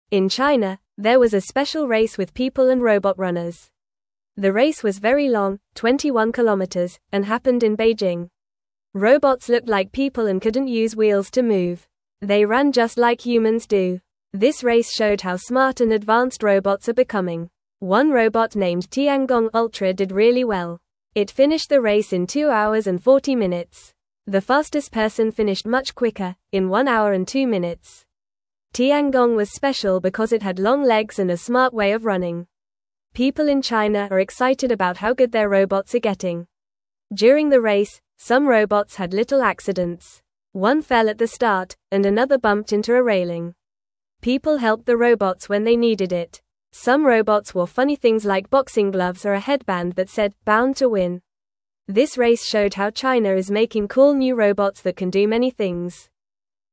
Fast
English-Newsroom-Beginner-FAST-Reading-Robots-and-People-Race-Together-in-China.mp3